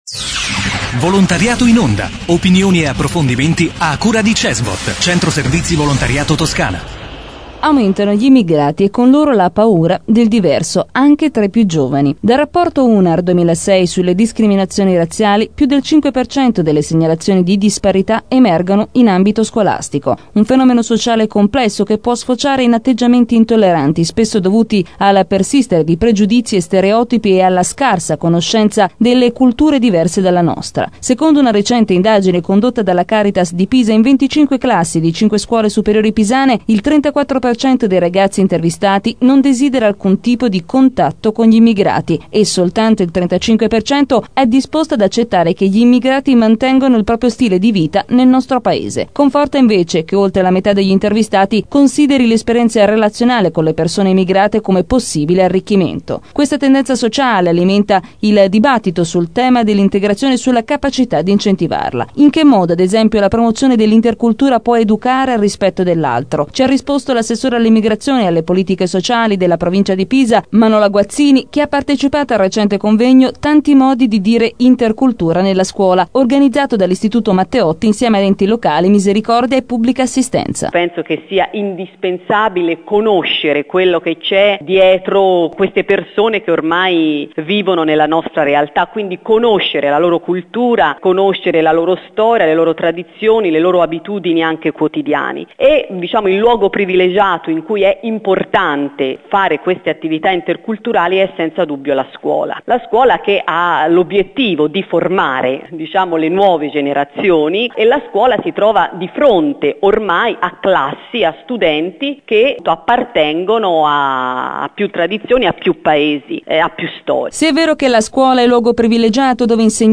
Intervista a Manola Guazzini, assessora all'immigrazione e alle politiche sociali della Provincia di Pisa